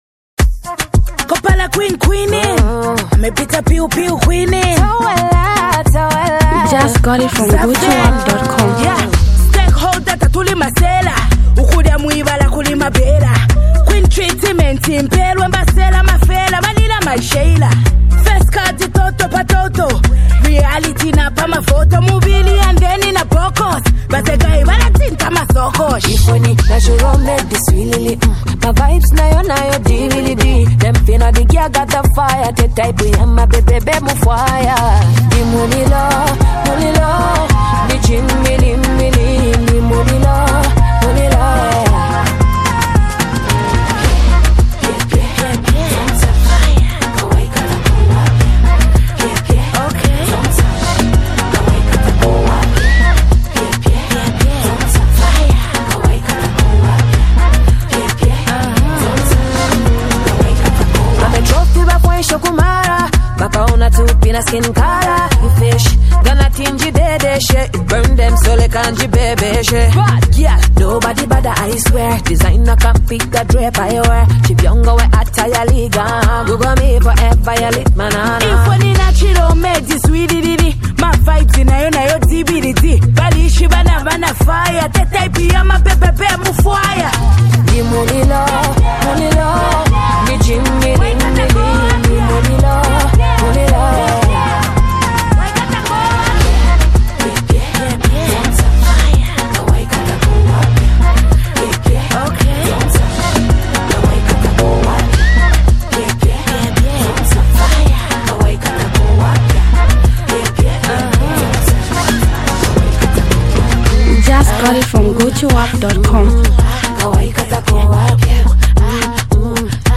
high-energy track
hardcore rap verses.